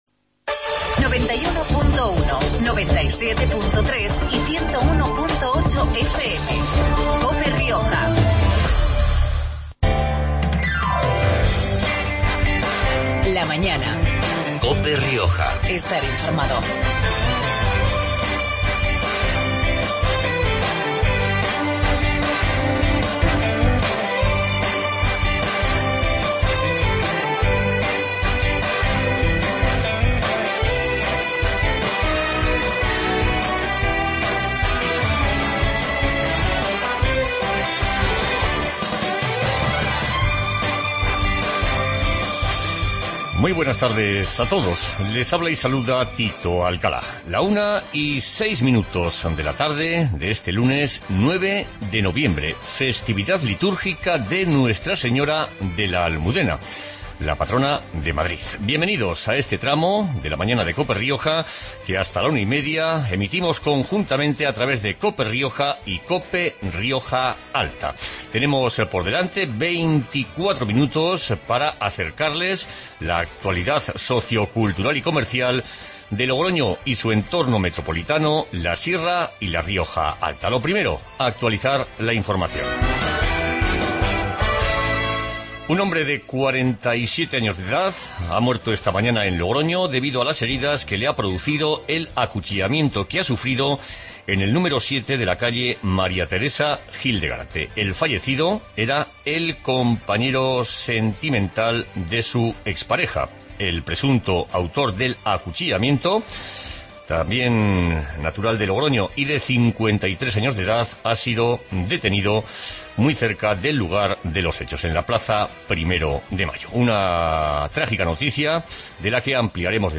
Magazine de actualidad riojana